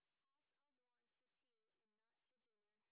sp14_white_snr20.wav